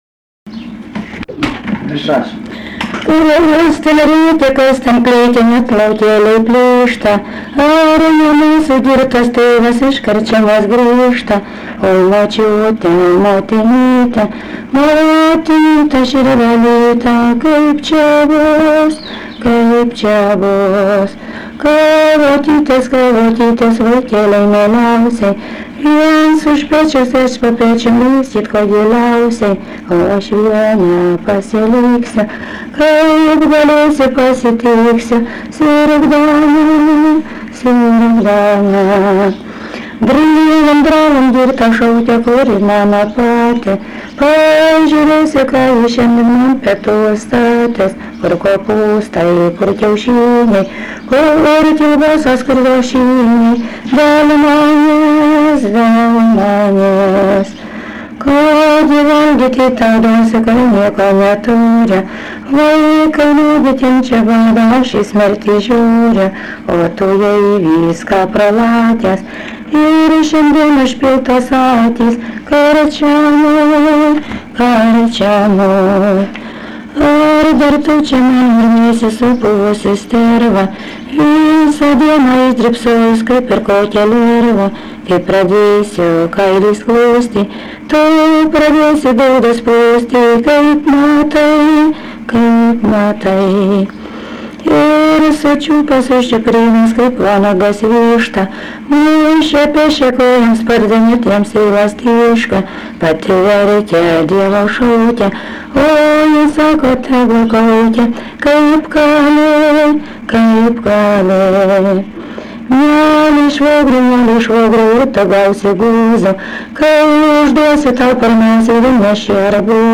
Dalykas, tema daina
Erdvinė aprėptis Ryžiškė
Atlikimo pubūdis vokalinis